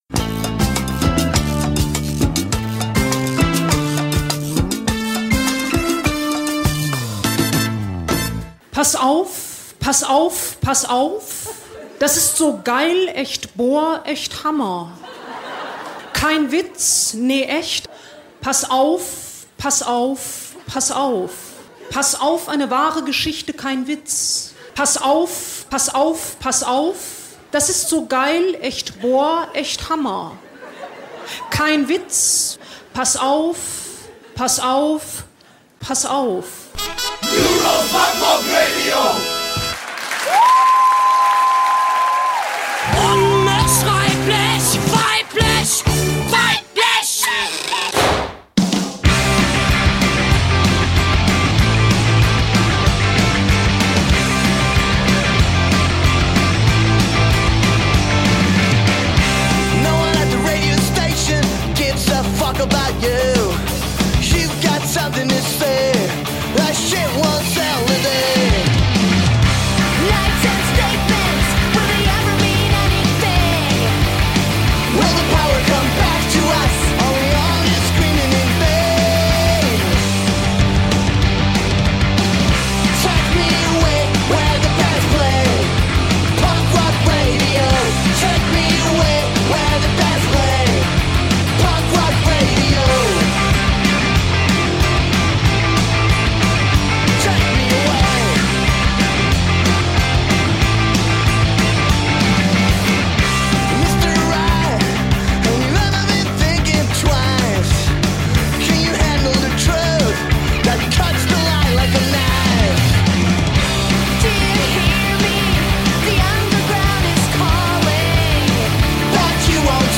100% Frauen-Quote sozusagen…